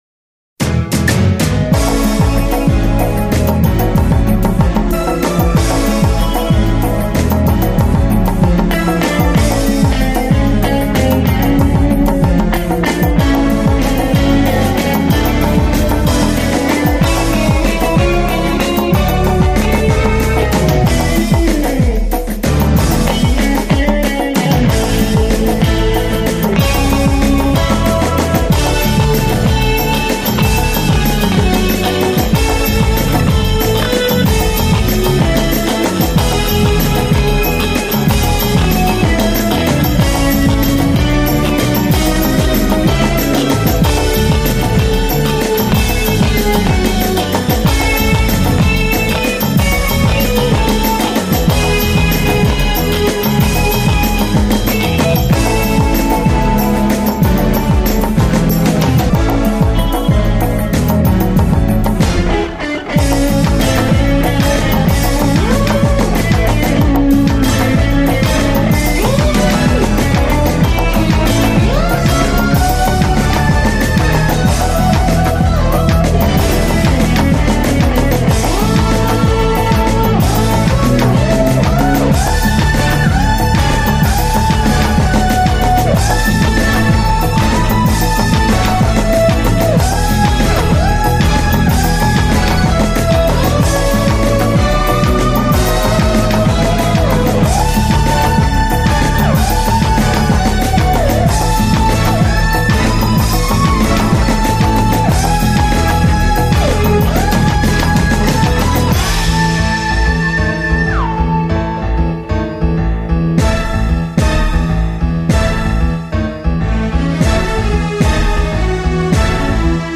(cover version)